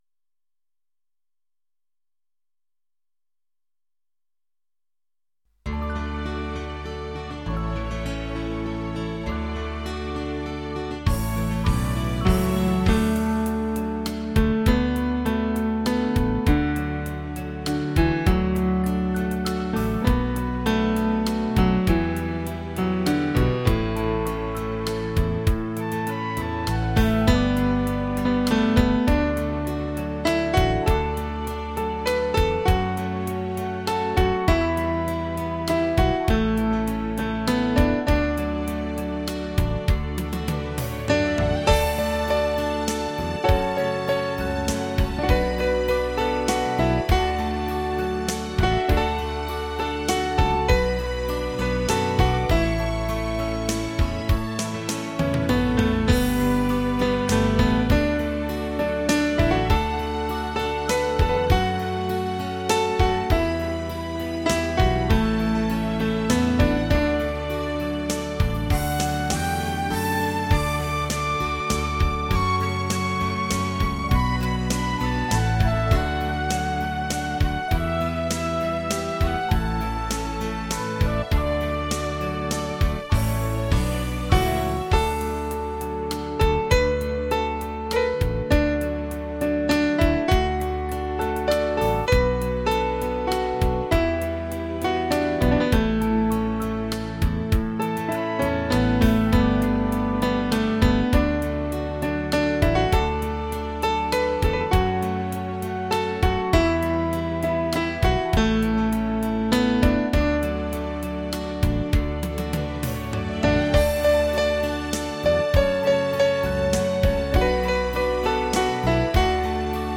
钢琴演奏
钢琴篇